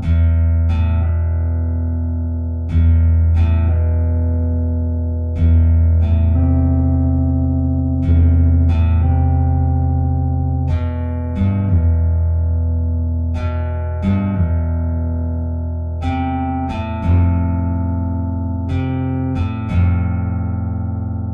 Guitar samples
Uses the spicy guitar vst. It's more of an Ostinato than chords. Technically a slow appregio?
Two bars per chord.